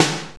NJS SNR 20.wav